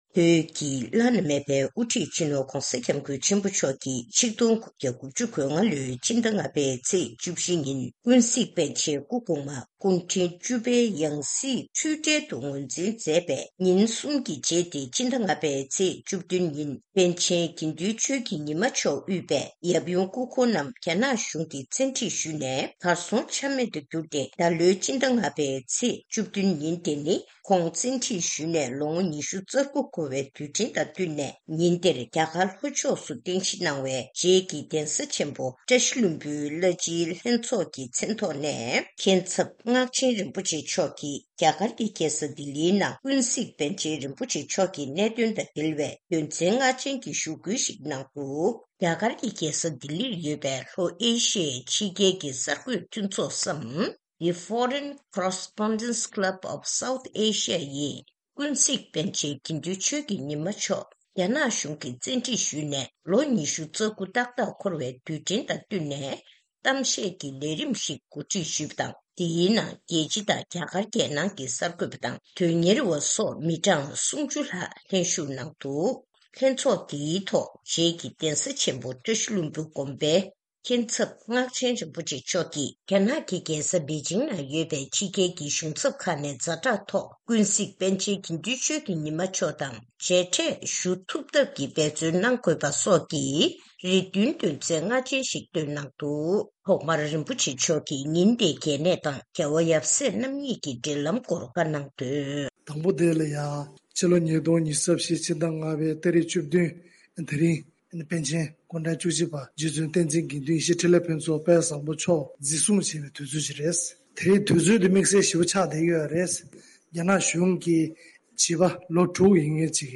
ཐེངས་འདིའི་གནས་འདྲིའི་ལེ་ཚན་ནང